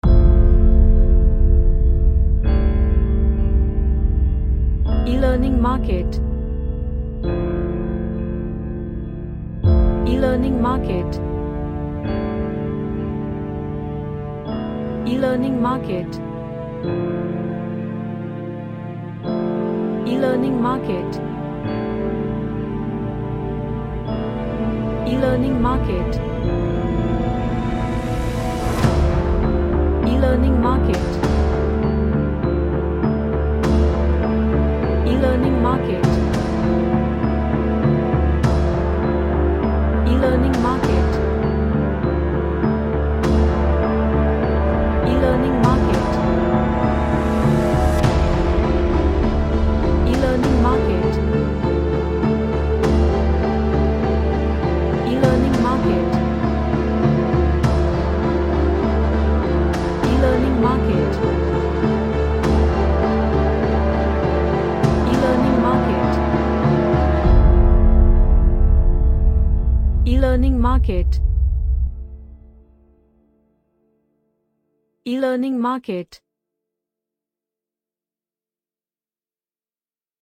A String Inspired trailer track.
Serious